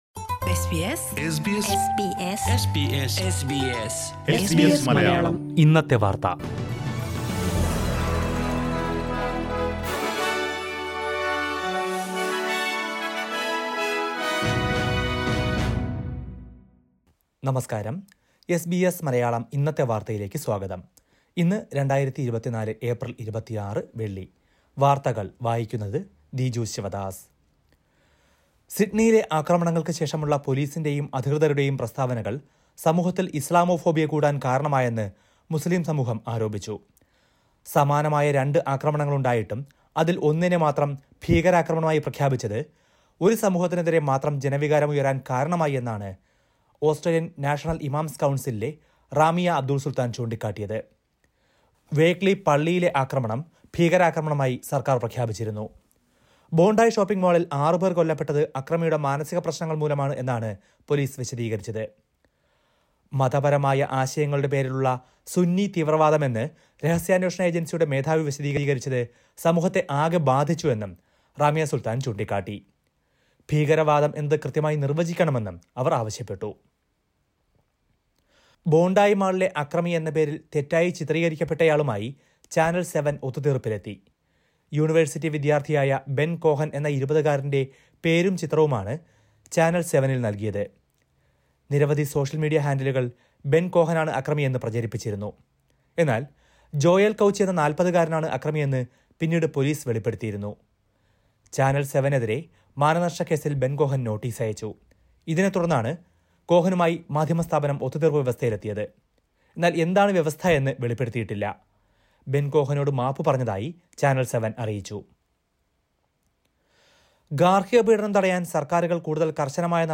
2024 ഏപ്രിൽ 26ലെ ഓസ്ട്രേലിയയിലെ ഏറ്റവും പ്രധാന വാർത്തകൾ കേൾക്കാം…